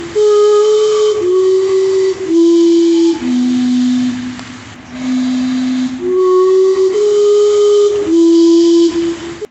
animal anime boing creature cute funny happy noise sound effect free sound royalty free Funny